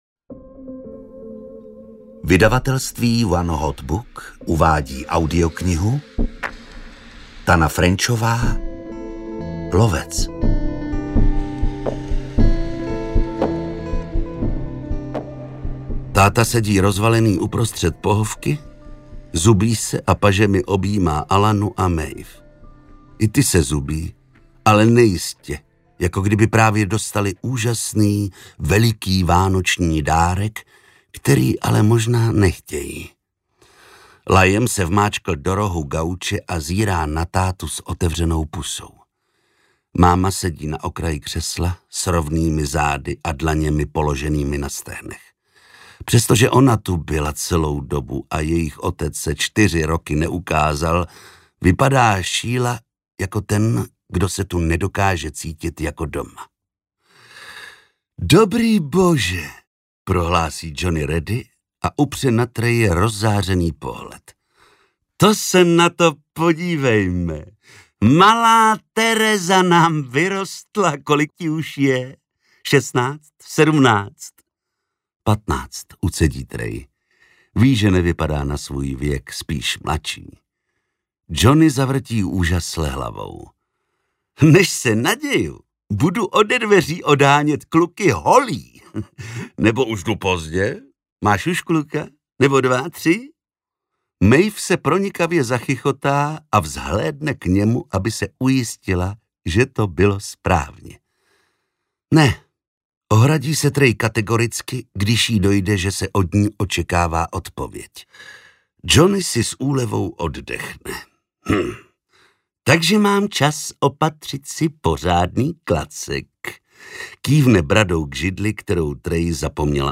Lovec audiokniha
Ukázka z knihy
• InterpretZdeněk Hruška